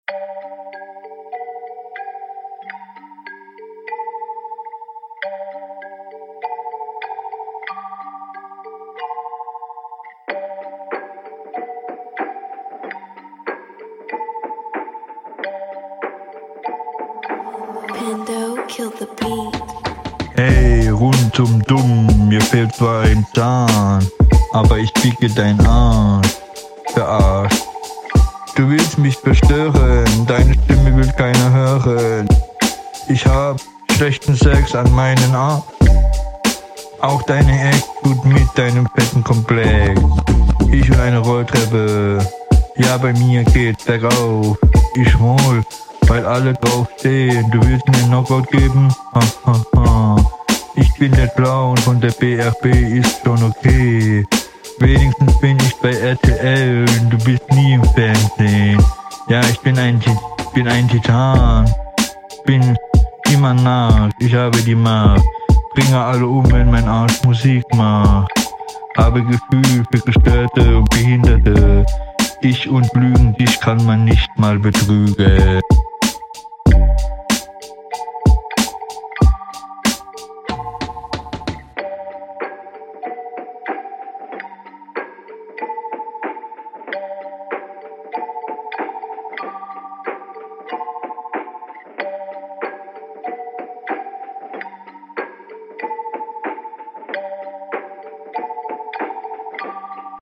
Ja du sprichst halt iwo neben dem Beat, mit nem "ich bin gerade aufgestanden Swag".
Flow ist nicht vorhanden. Stimme absolut unverständlich.